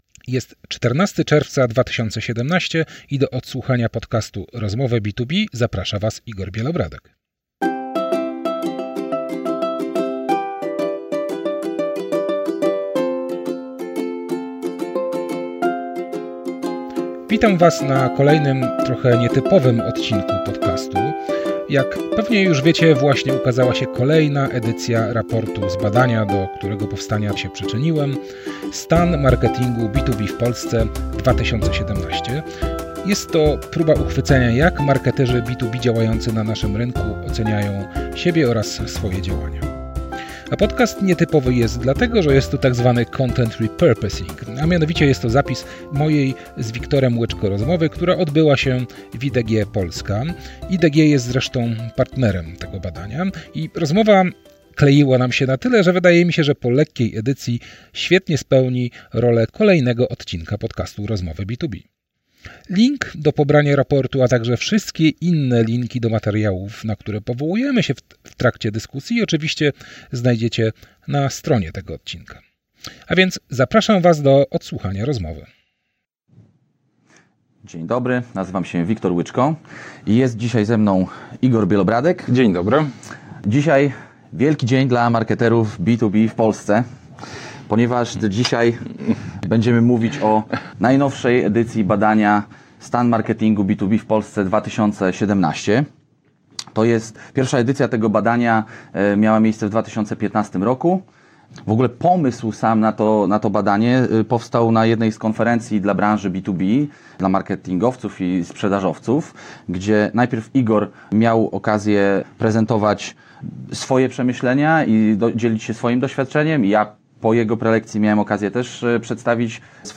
A że rozmowa była udana, myślę że z powodzeniem spełni rolę kolejnego odcinka podcastu.